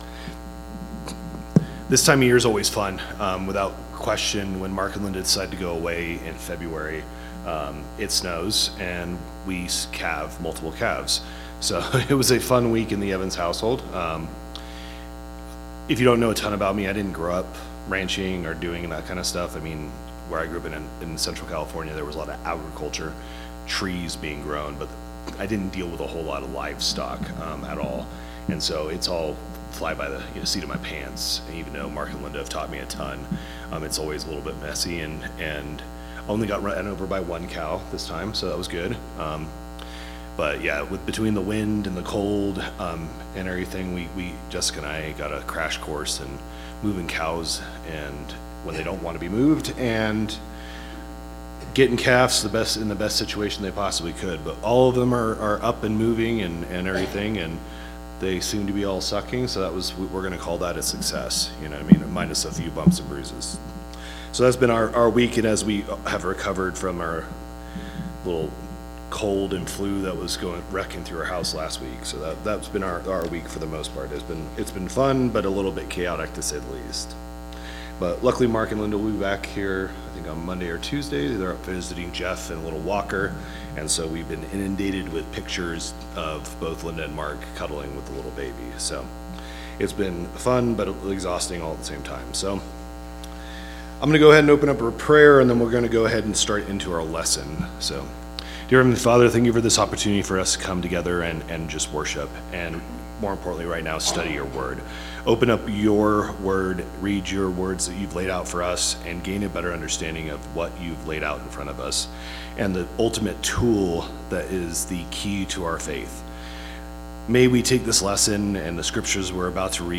Bible Class 02/22/2026 - Bayfield church of Christ
Sunday AM Bible Class